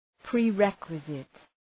Προφορά
{prı’rekwızıt}